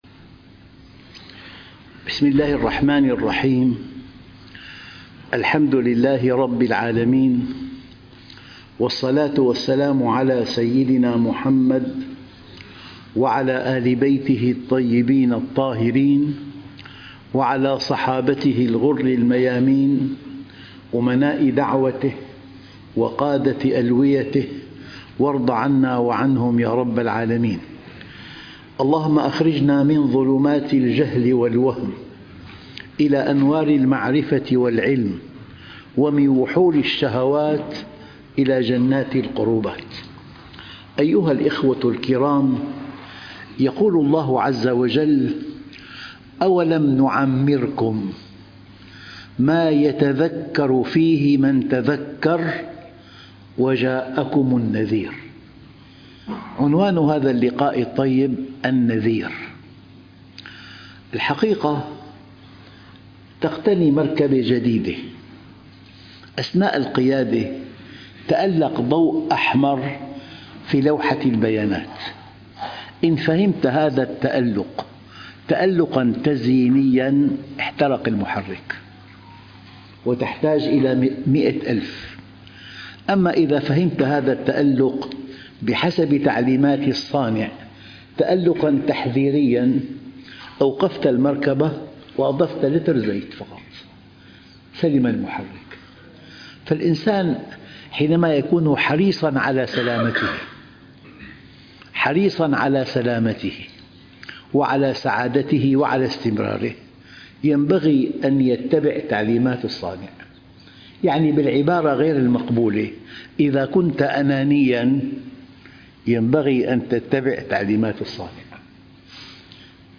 الأردن - عمان - جامع التقوى - دروس صباحية - الدرس 018 - النذير - الشيخ محمد راتب النابلسي